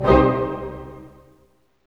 Index of /90_sSampleCDs/Roland LCDP08 Symphony Orchestra/HIT_Dynamic Orch/HIT_Orch Hit Min
HIT ORCHMI02.wav